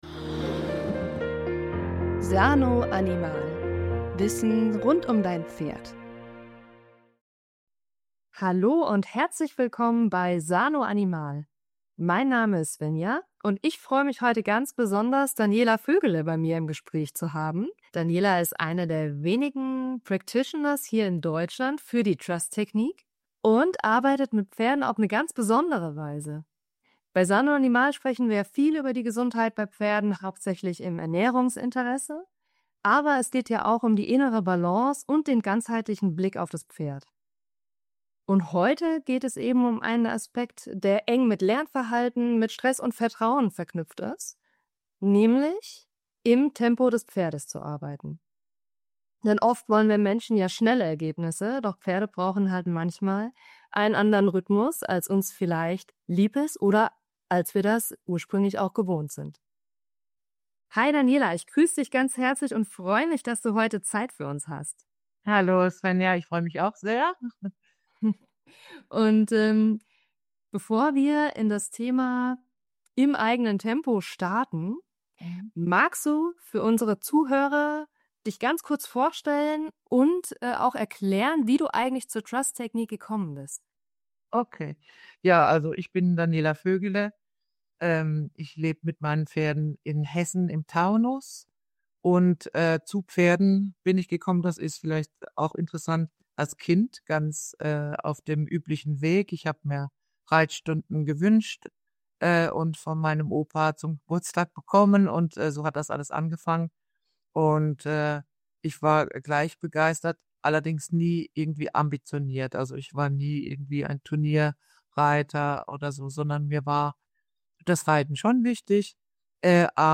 Unser Gast im Fokus